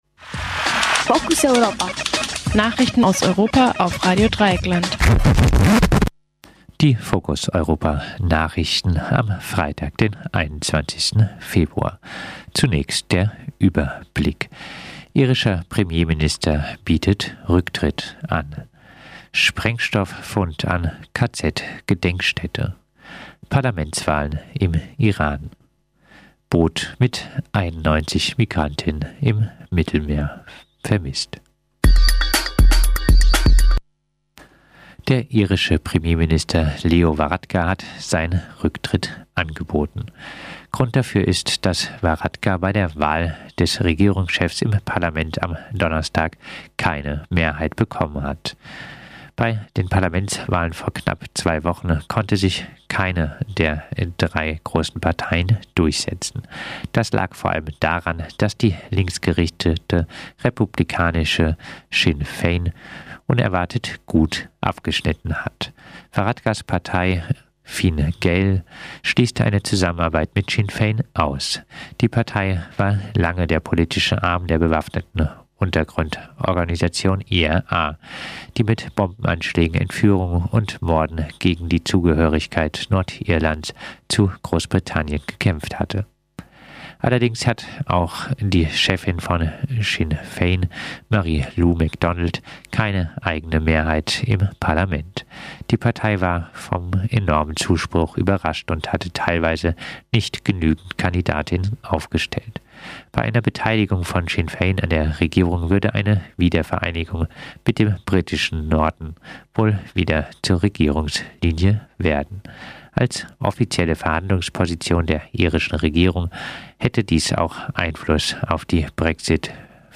Focus Europa Nachrichten vom Freitag, dem 21. Februar